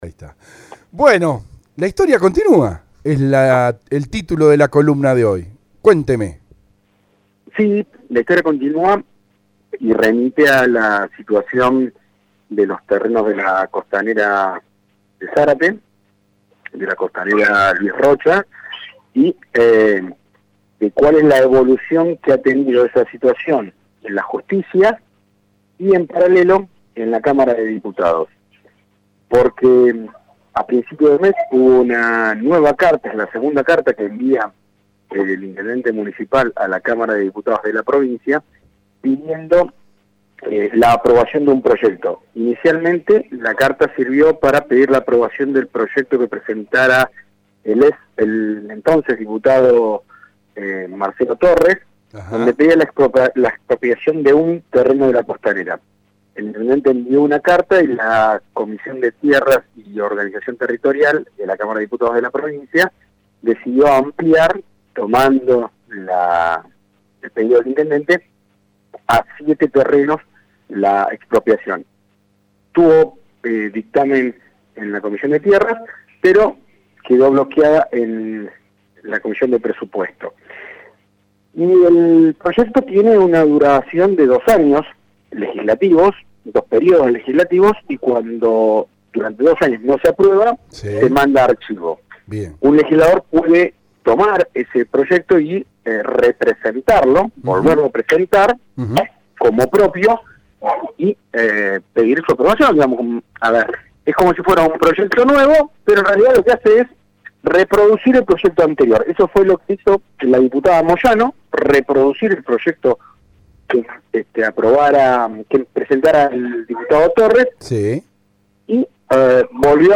Esto se habló en radio EL DEBATE, en el ultimo programa del invierno.